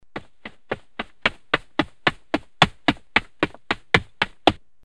PASOS CORRIENDO01 PASOS CORRIENDO
Ambient sound effects
pasos_corriendo01_pasos_corriendo.mp3